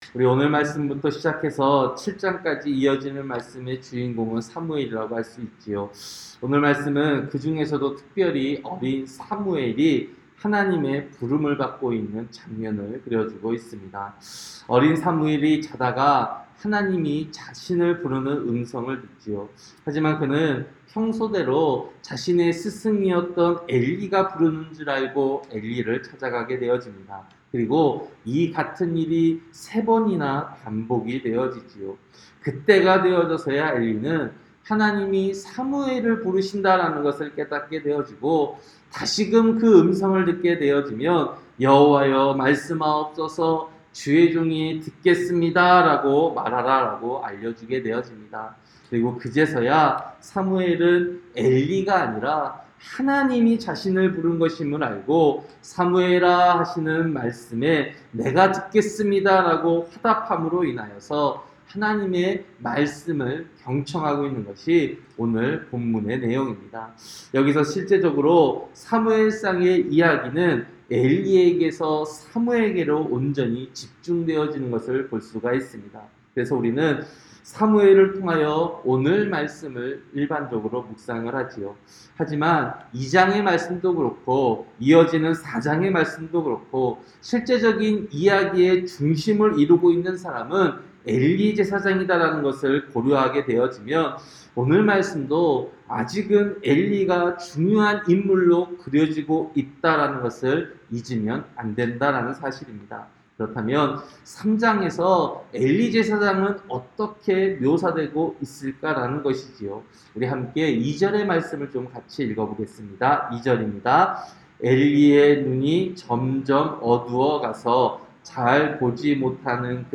새벽설교-사무엘상 3장